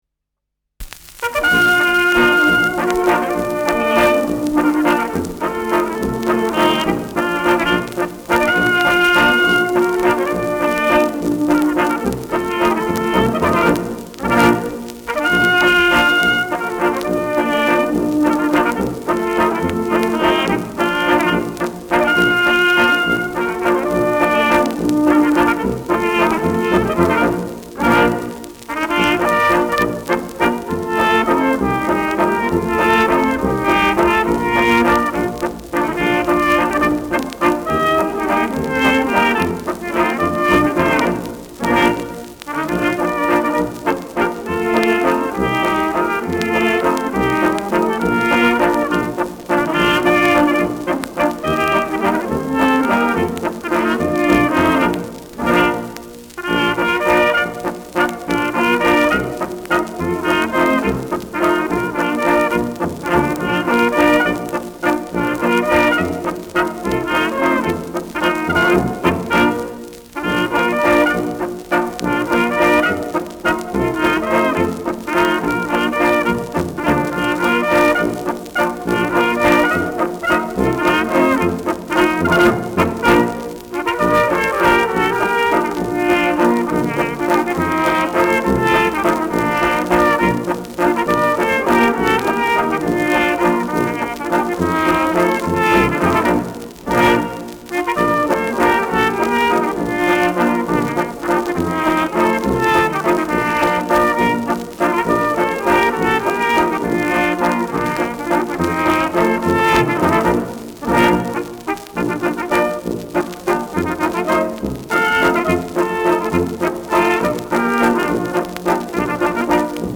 Schellackplatte
leichtes Knistern